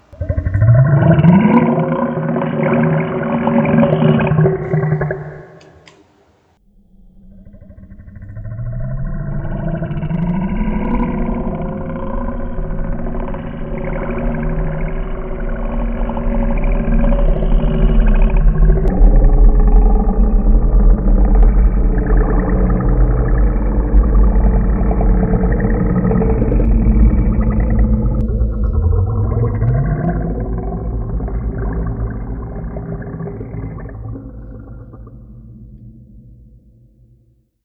Burp Stretch
Alien Ambient Audio Background Badoink Burp Dub Dubstep sound effect free sound royalty free Nature